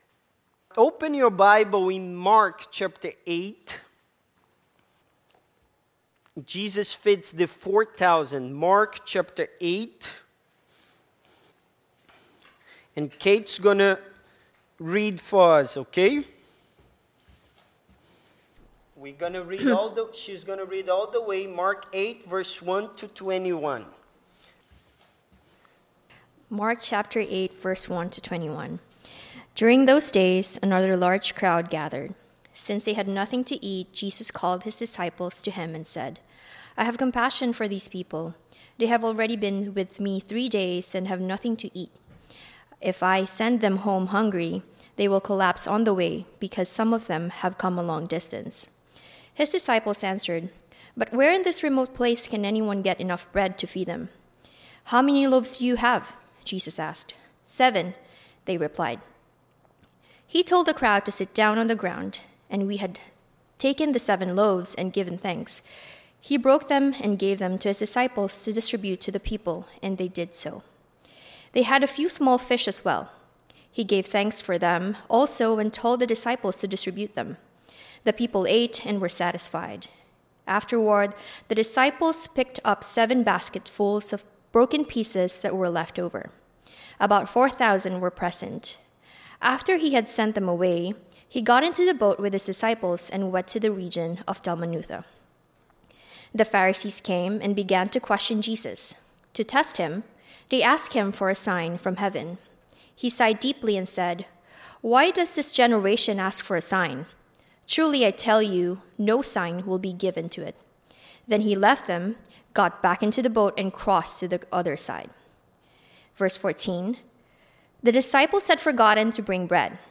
The Life of our Lord Passage: Mark 8:1-21; Matthew 15:29-39 Service Type: Sunday Morning Topics